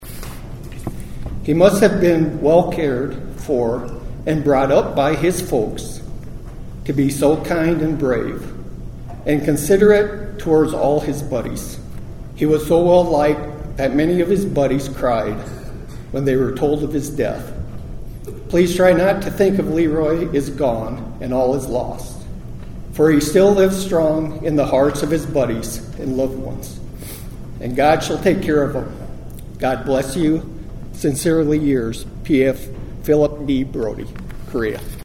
ONIDA, (KCCR) — Students and residents filled the Sully Buttes High School gym Wednesday afternoon to honor a local Fallen Hero by dedicating a bridge in his name.